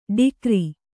♪ ḍikri